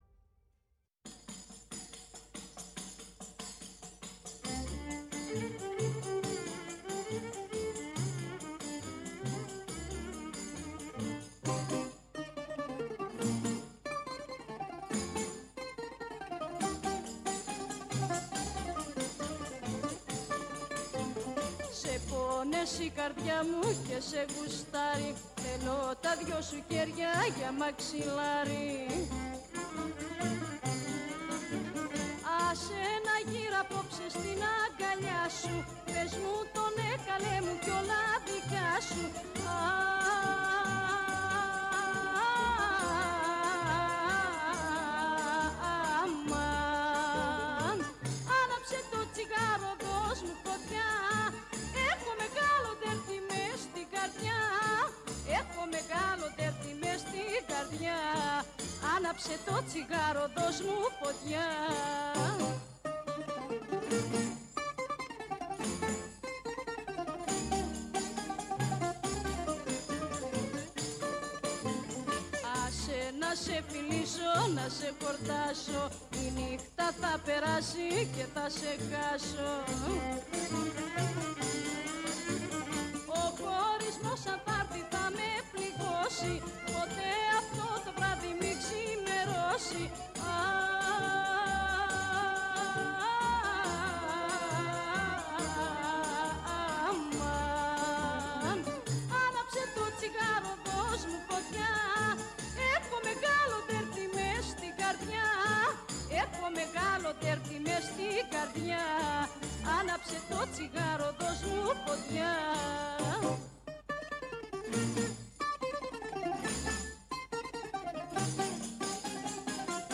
στο στούντιο της Φωνής της Ελλάδας
Συνεντεύξεις